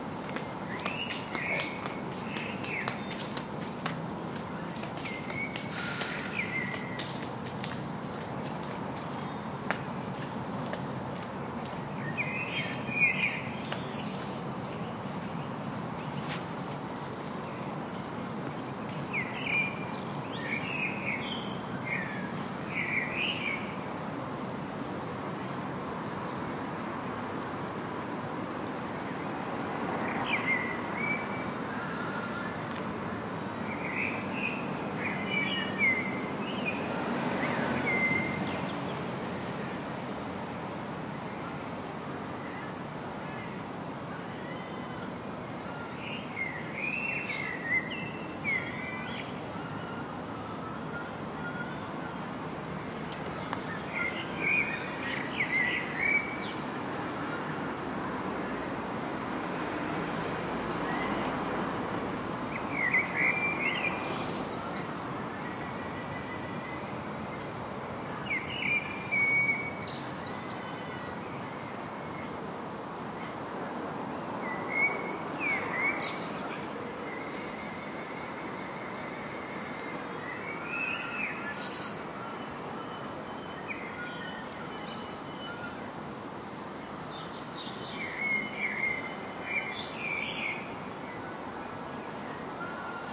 晨鸟
描述：凌晨4点的鸟叫声
标签： 鸟啁啾 上午 唱歌 郊区
声道立体声